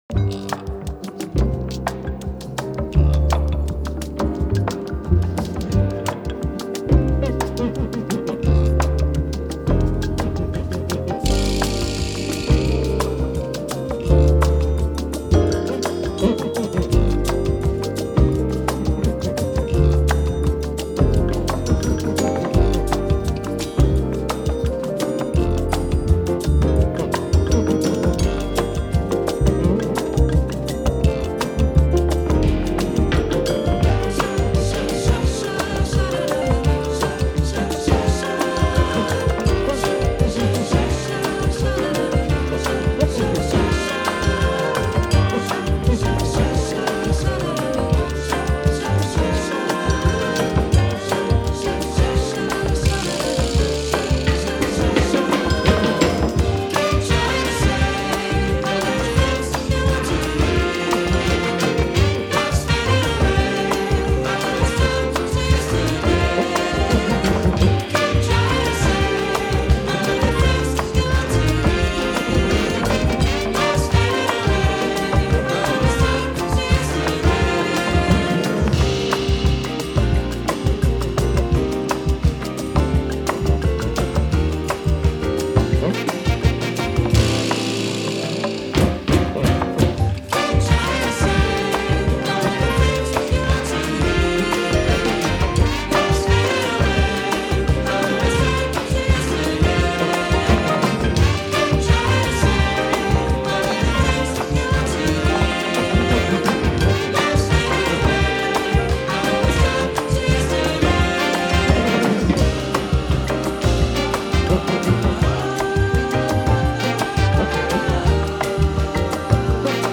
ダイジェスト音源